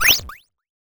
whistle.wav